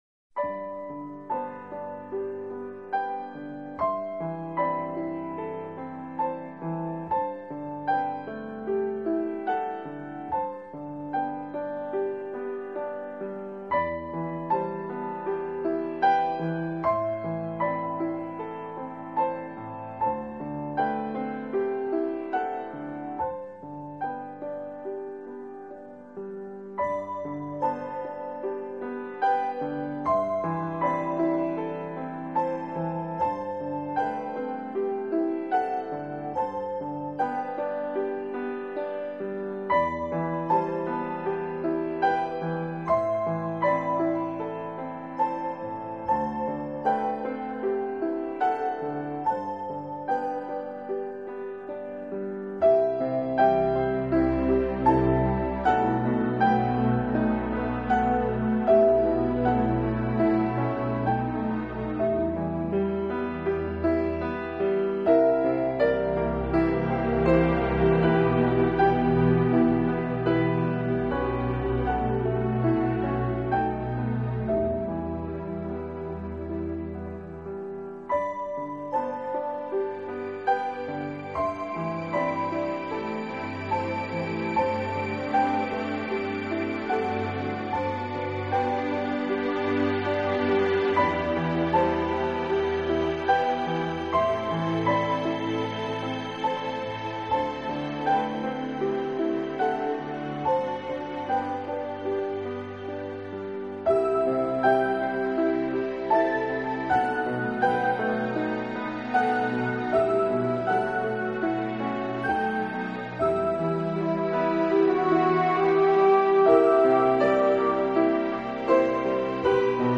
【钢琴纯乐】
音乐风格：New Age